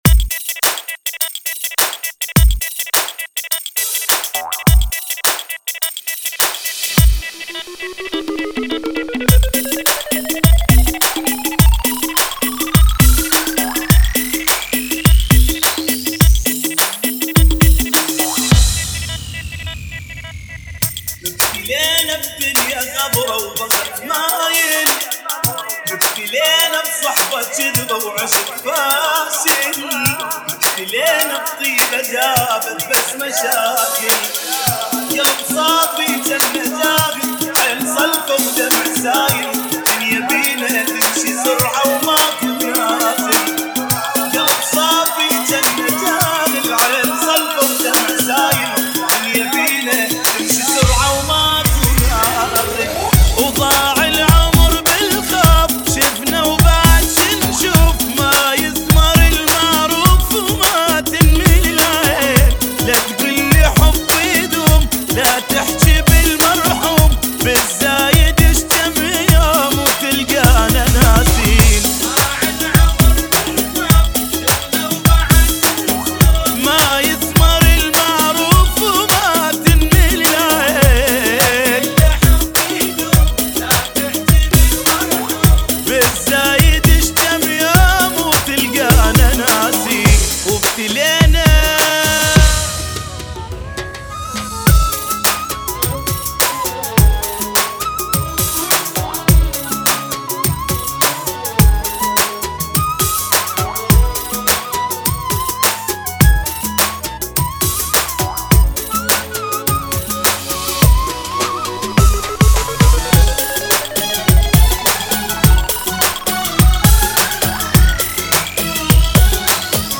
[ 104 Bpm ]